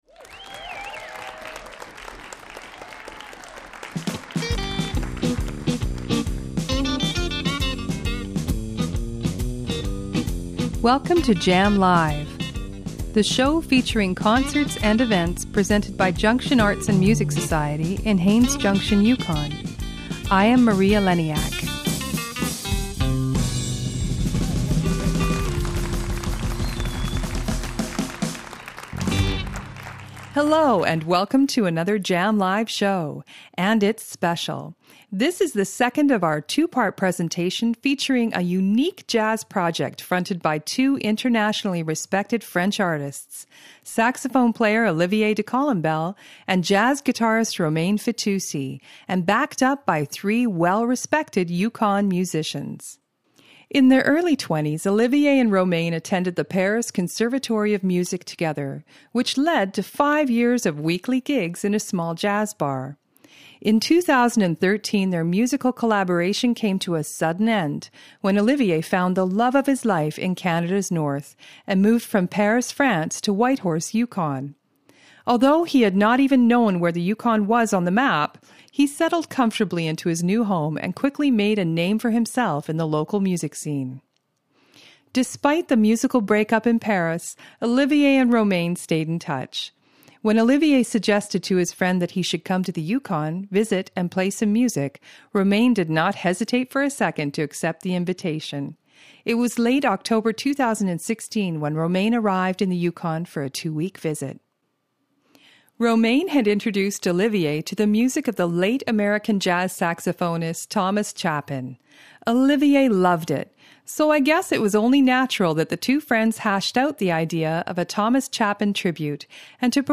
Live music recorded in Haines Junction, Yukon.